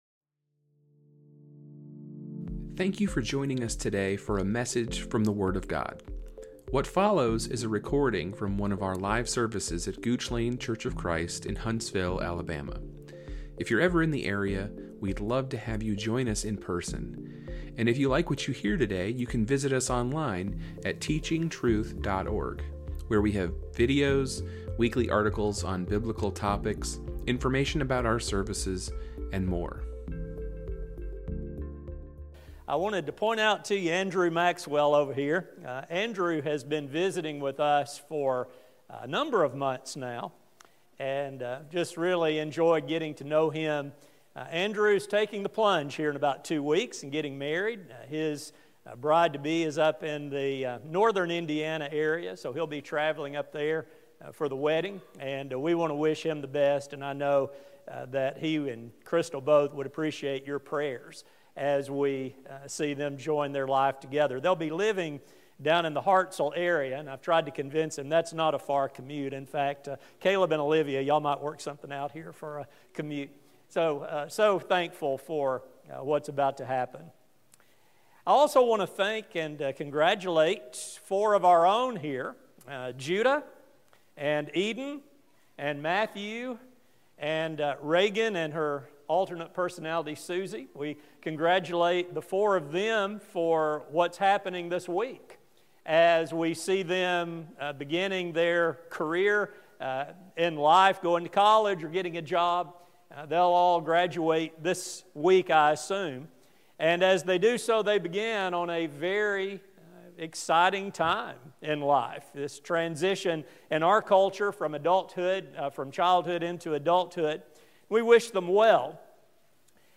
God did not leave youth in the dark about how to prepare; in fact, the longest of the recorded psalms has a section specifically addressed to youth. A sermon